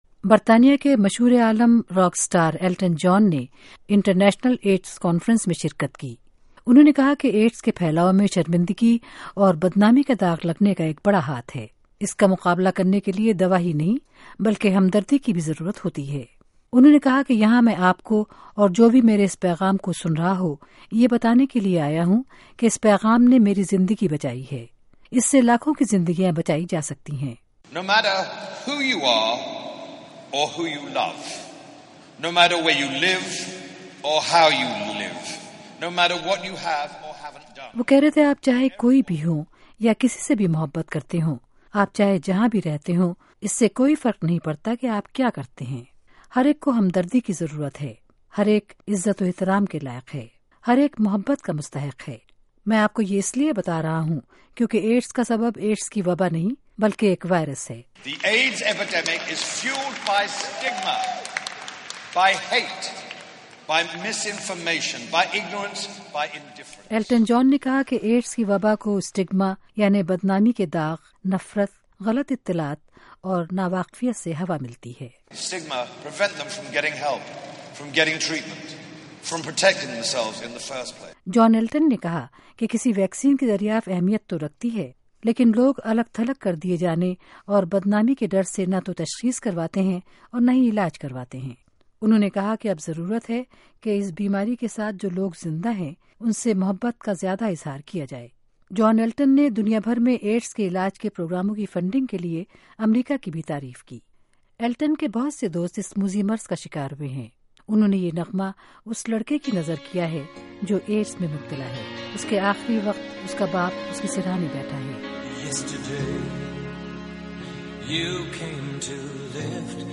تقریب کی رپورٹ سنیئے